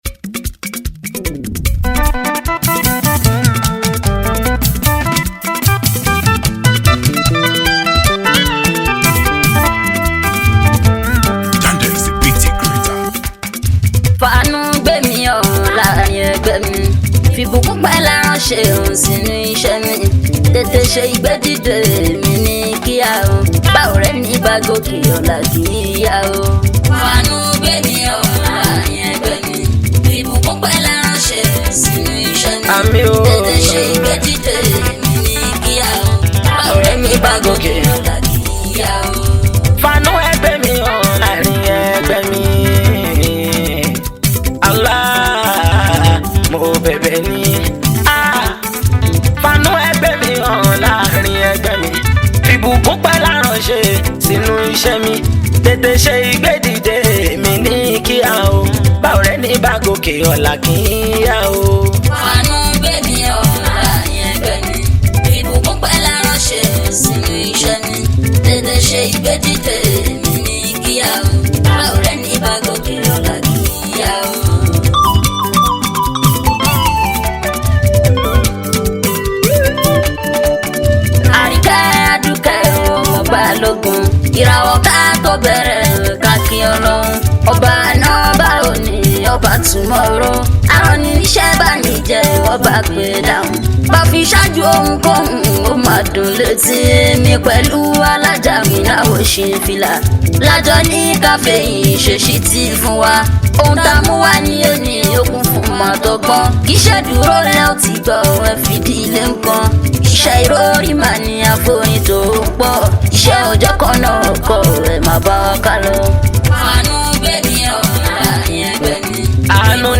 Yoruba Islamic Music 0
Nigerian Yoruba Fuji track